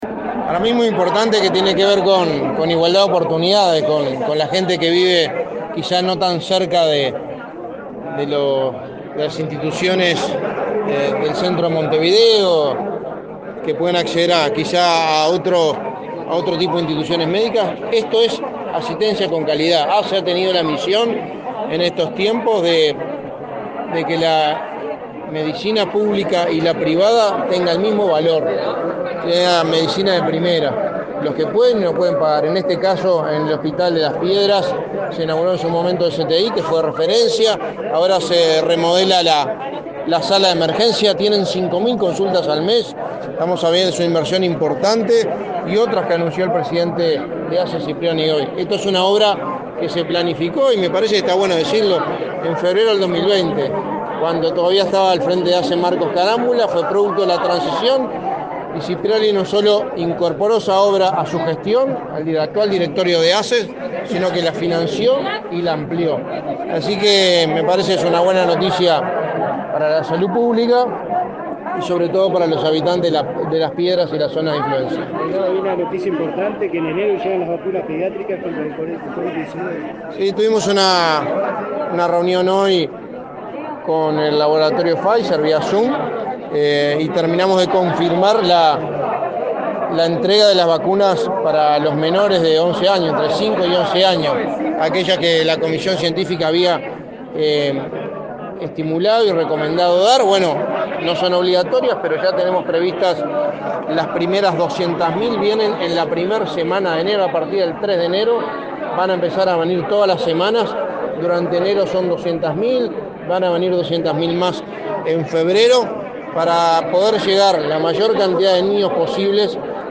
Declaraciones a la prensa del secretario de Presidencia
El secretario de Presidencia, Álvaro Delgado, dialogó con la prensa sobre el arribo a nuestro país de vacunas contra la COVID-19 para inocular a niños